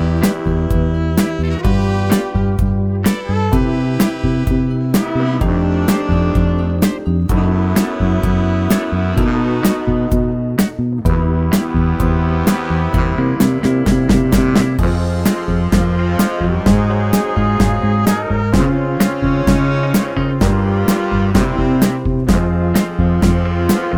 no Backing Vocals Soul / Motown 3:12 Buy £1.50